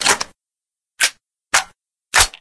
reloadTurret.ogg